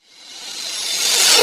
REVERSCYM1-L.wav